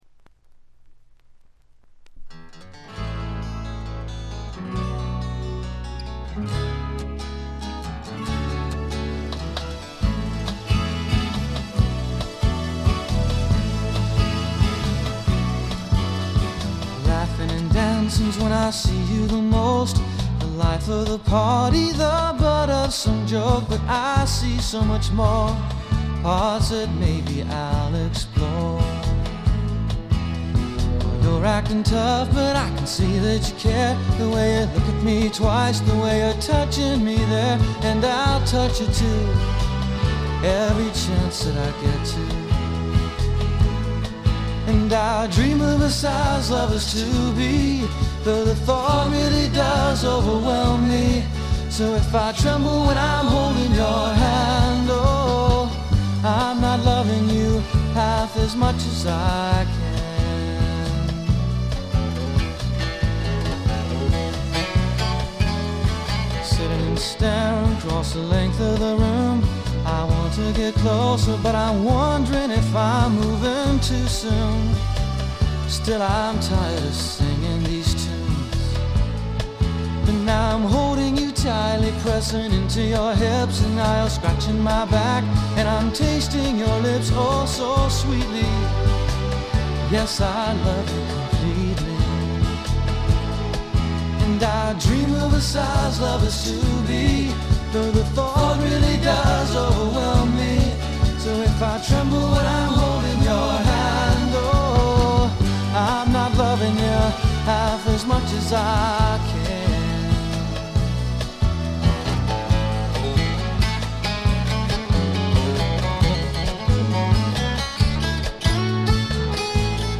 内容はポップでAOR的なサウンドが印象的なシンガー・ソングライター・アルバム。
試聴曲は現品からの取り込み音源です。